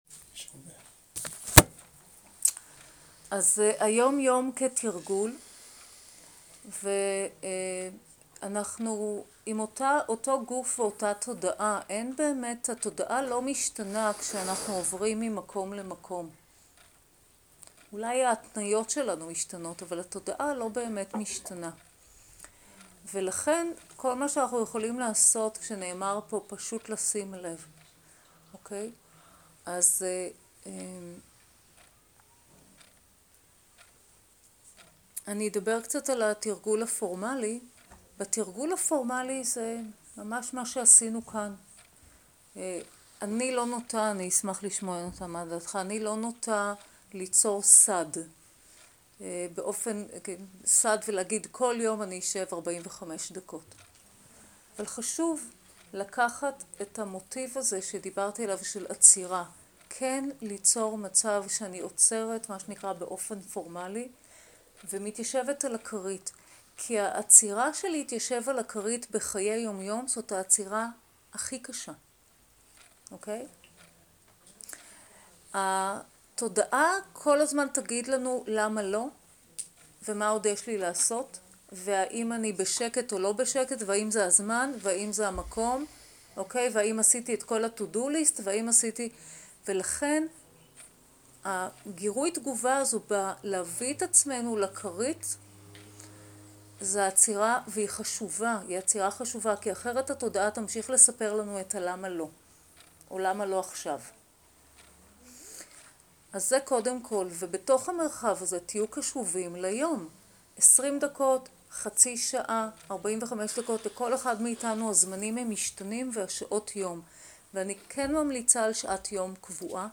שיחת דהרמה בחיי היום יום (שאלות ותשובות)